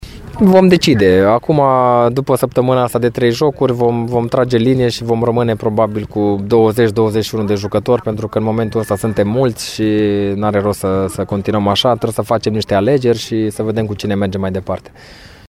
Paul Codrea spune că se va decide în următoarele zile asupra jucătorilor aflați în probe: